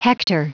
Prononciation du mot : hector
hector.wav